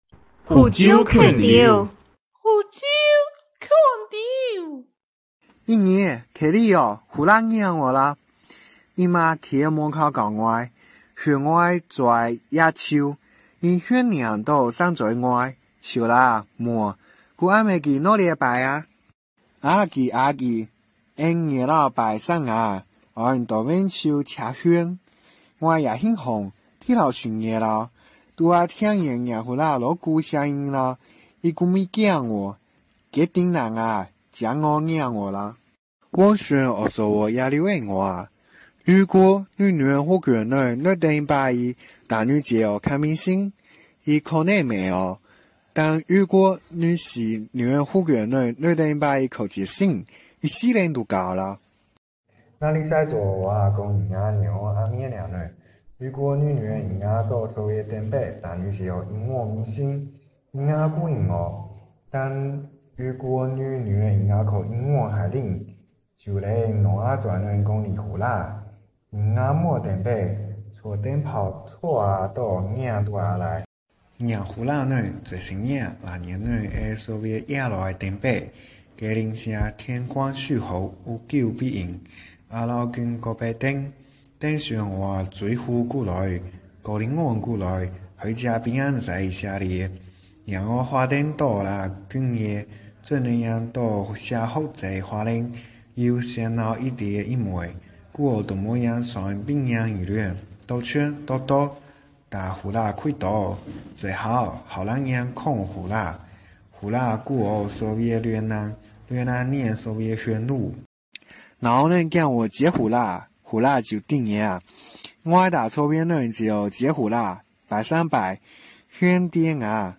兄弟～福州腔调旨在展现年轻人使用方言的有趣情景。